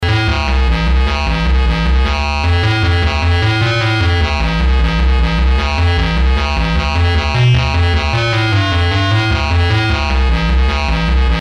FET VCLPF Schematic
This makes the filter quite noisy because any noise also gets amplified.
mosfetsample2.mp3